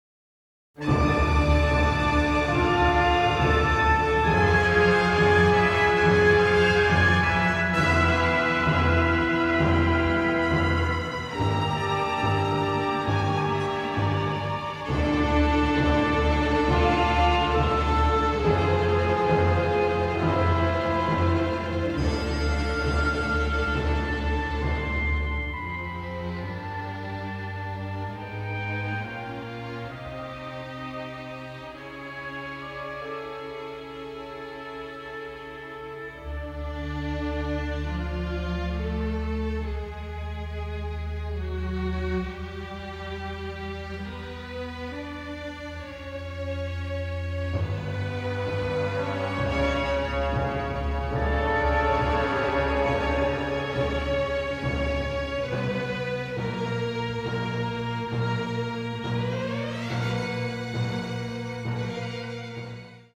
a symphonic orchestral score